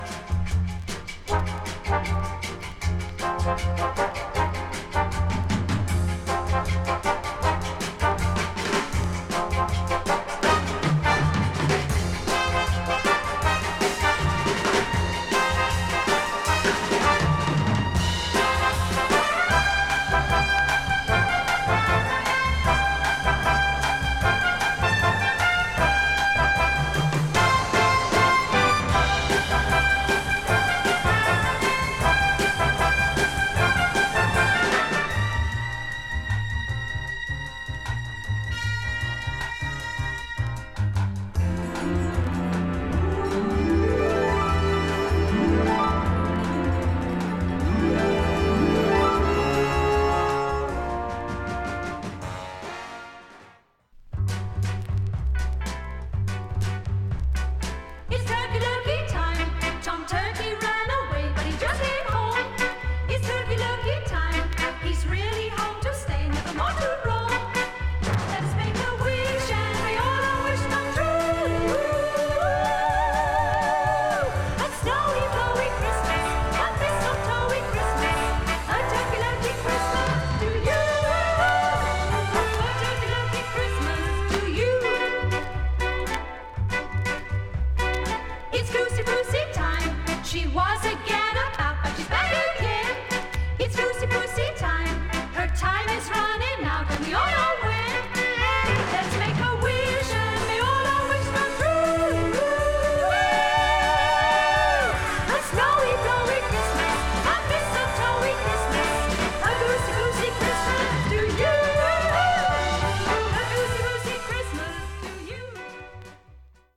ミュージカル
めくるめくスキャットと華麗なアレンジが見事な
女の子３人で歌うアッパーキラーカバー
ハイテンションなソフトロック
甘いアコギが優しい♀Vo.の